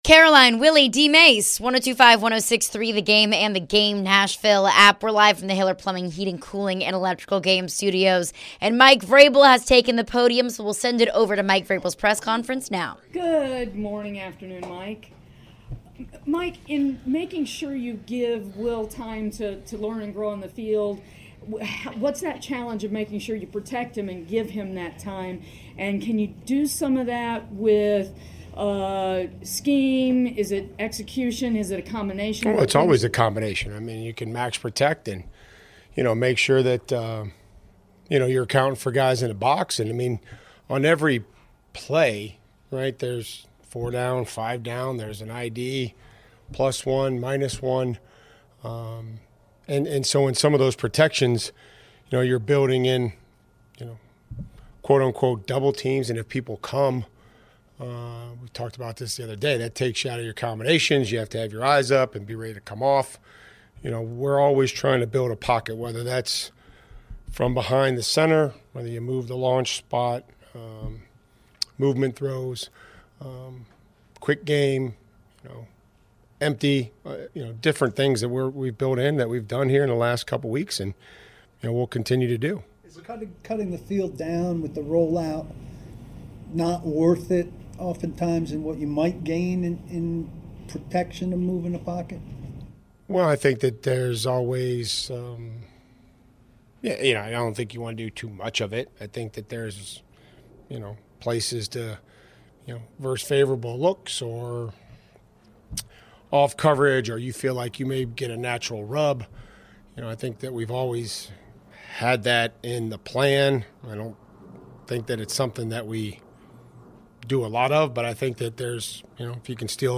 Later in the hour, we hear from now-starting QB Will Levis speak to the media and his initial thoughts on being named the starter.